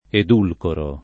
edulcoro [ ed 2 lkoro ]